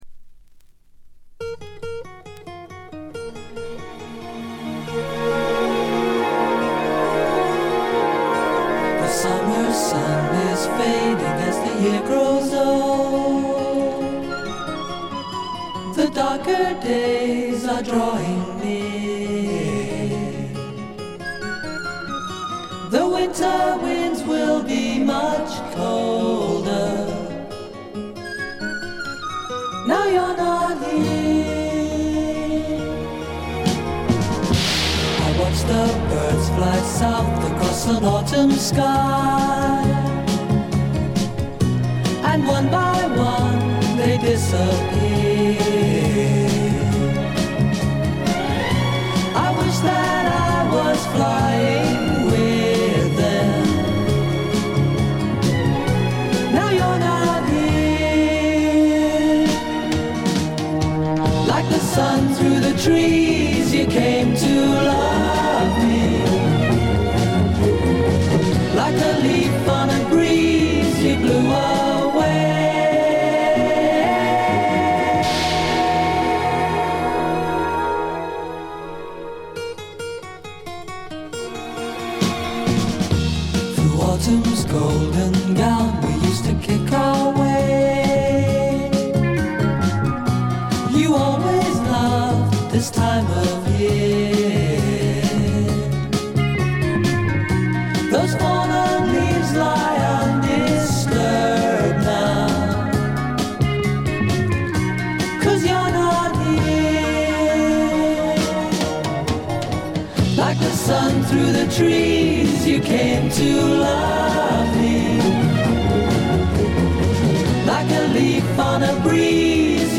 英国のポップ・デュオ
いかにも英国らしい繊細で哀愁感漂う世界がたまらないです。
試聴曲は現品からの取り込み音源です。